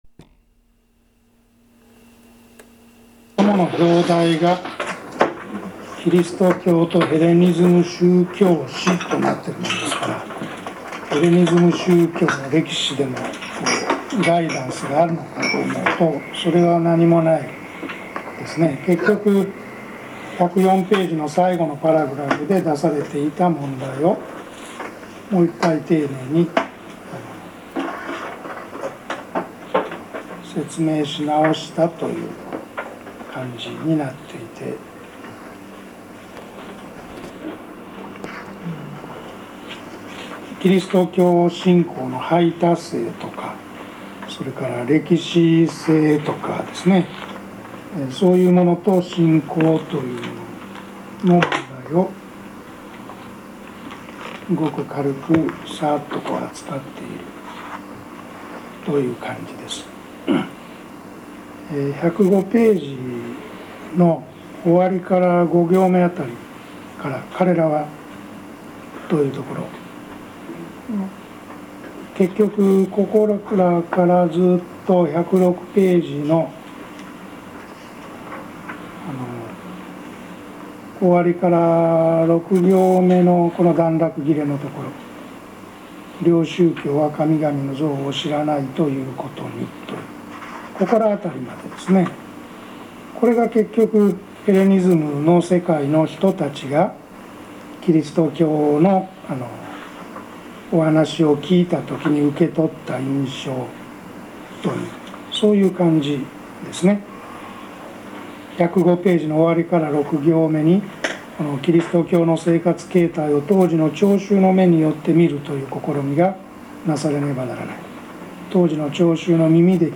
改革派神学研修所における講義録音